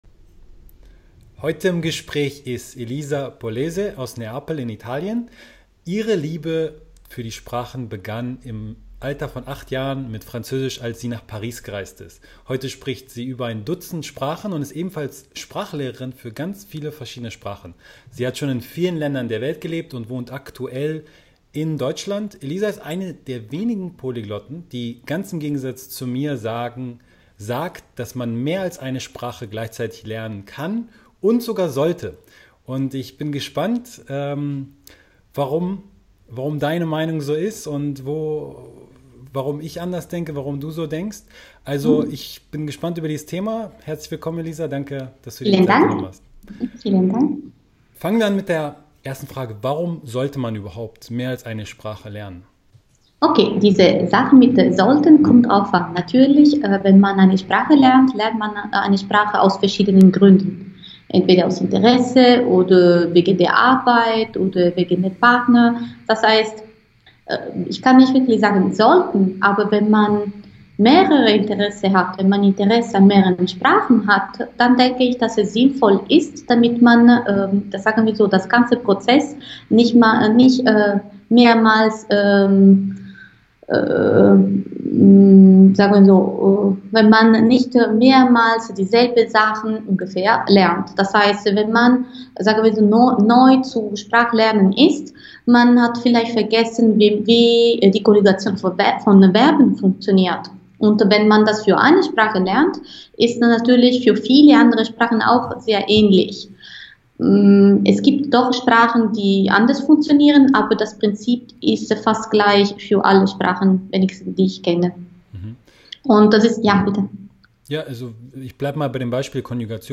Mehrere Sprachen gleichzeitig lernen, geht das? Interview